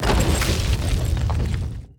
Free Fantasy SFX Pack
Rock Wall 1.ogg